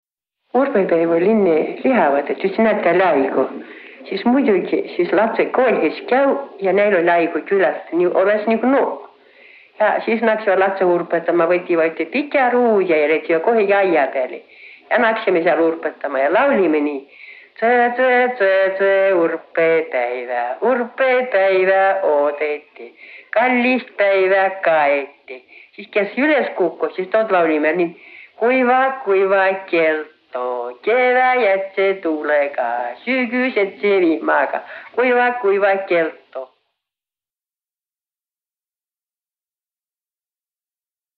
Urbõpäävä_seletüs_ja_laul.mp3